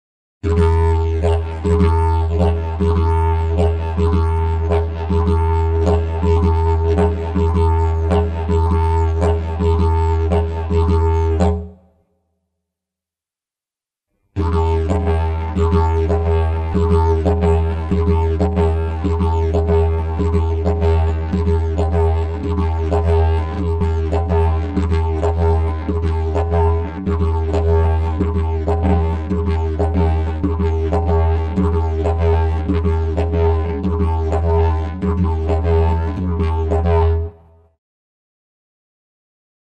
Cioè, durante una singola inspirazione, il movimento di chiusura della mandibola verrà diviso in due scatti in modo da ottenere due suoni distinti uno dietro l’altro.
Sample n°39 contiene: esecuzione del double jaw.